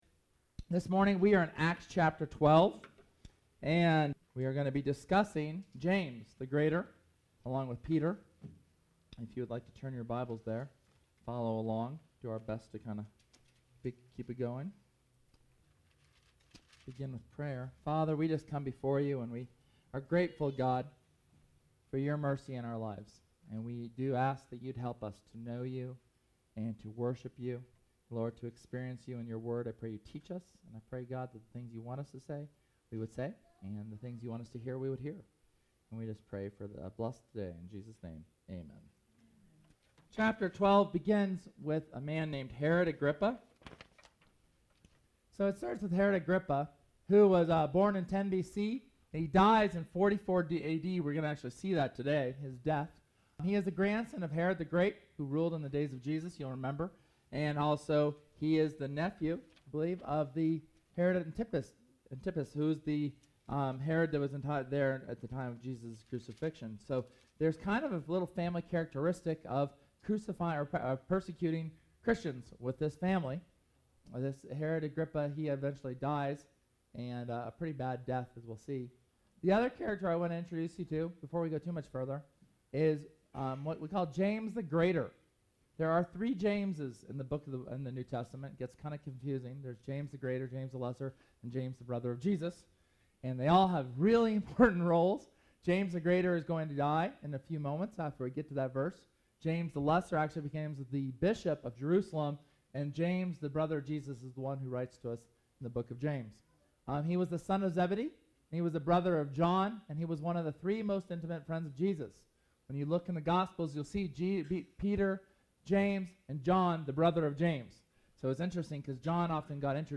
SERMON: Persecution & Prayer – Church of the Resurrection
Sermon from August 4th, 2013 upon the martyrdom of James the Greater and the subsequent deliverance of Peter from prison.